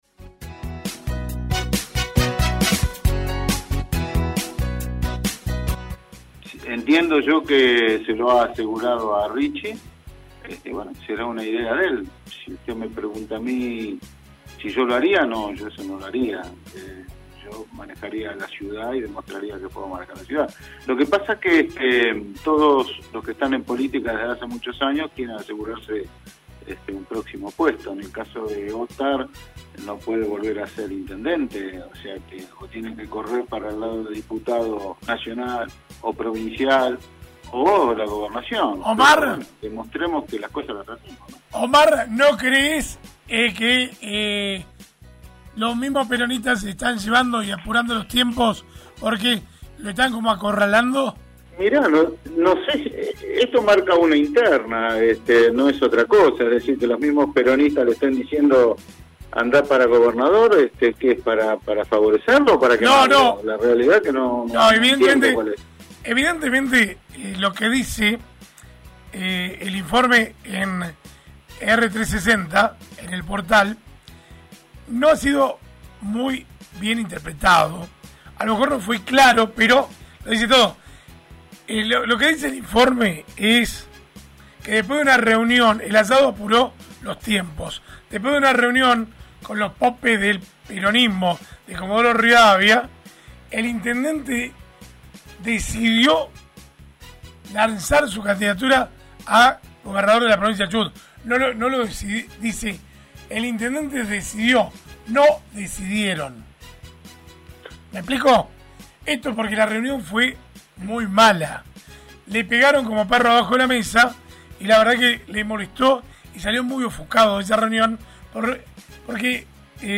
El concejal del Plich expresa su opinión respecto de la interna justicialista en el aire de RADIOVISIÓN.